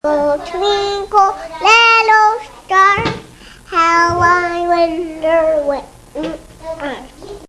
Everybody's favorite alert sound!
twinkle.mp3